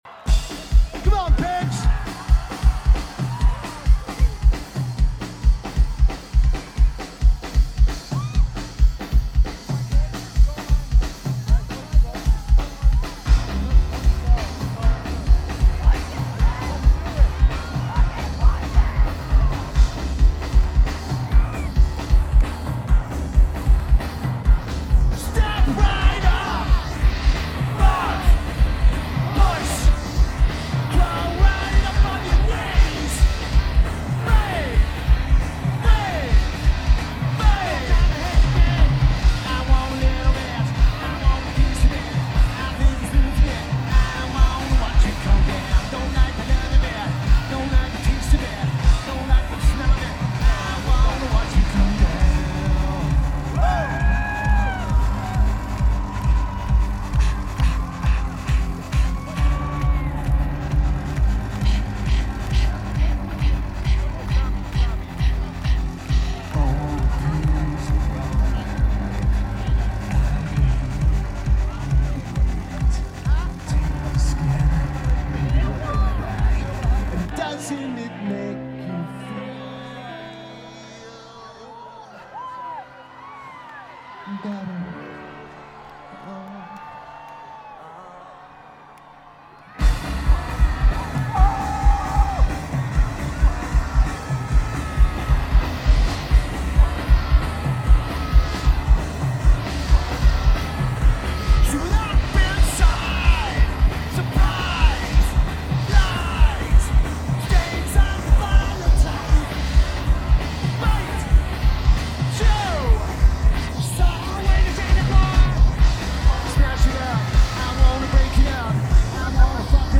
Shaky Knees Music Festival
Keyboards/Bass/Backing Vocals
Drums
Guitar
Notes: A face melting festival set